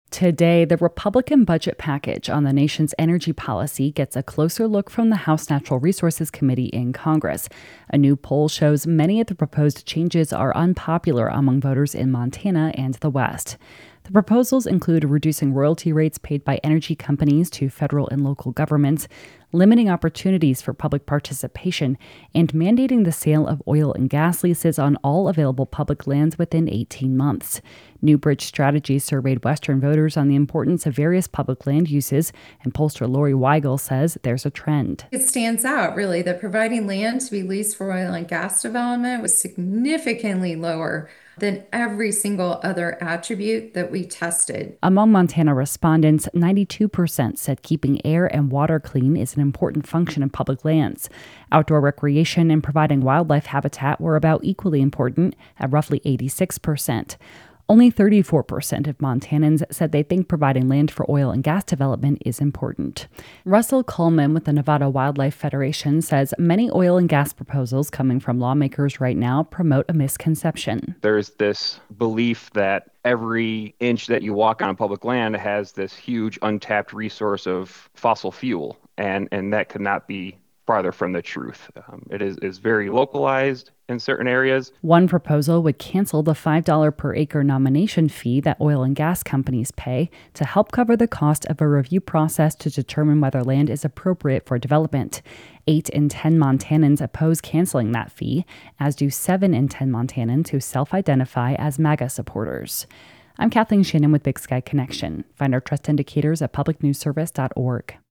Big Sky Connection - The U.S. House Natural Resources Committee will mark up the Republicans’ budget package today (Tuesday), including policies supporting the Trump administration’s “National Energy Dominance” agenda. A new poll of Westerners shows many voters – including MAGA supporters – disagree with the proposals. Comments by pollster